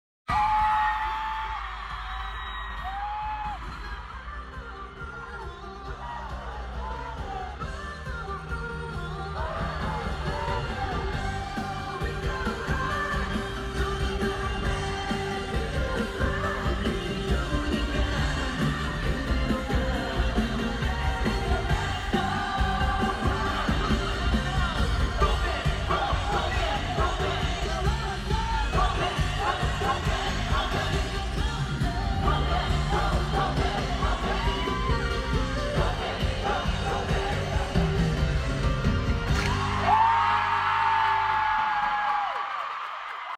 Dallas Day 1. I was so far away😭